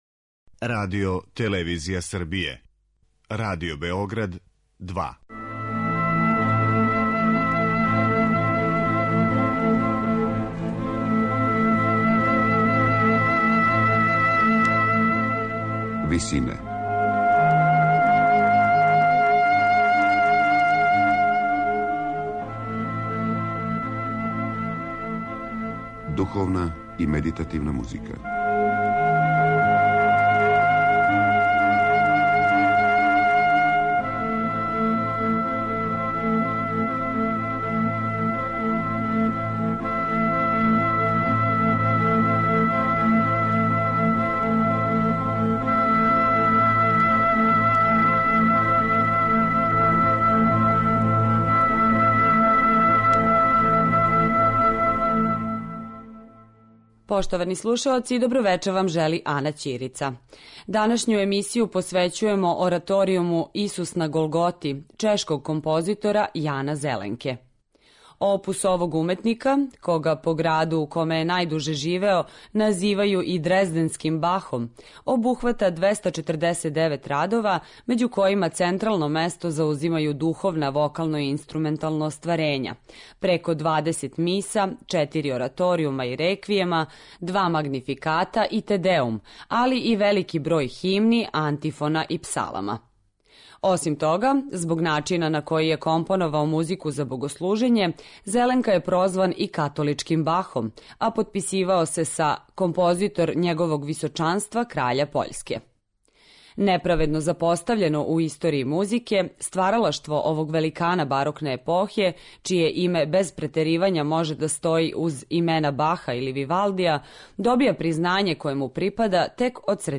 Од многих композиционих поступака по којима је Зеленка препознатљив, у овом делу су најприсутнији примена високо колористичког третмана хроматике, као и замисли које су „разигране" по читавом оркестарском парту, понекад унисонo или кроз необичне ритмичке и мелодијске мотиве, који највероватније потичу из чешке фолклорне музике.
у ВИСИНАМА представљамо медитативне и духовне композиције аутора свих конфесија и епоха.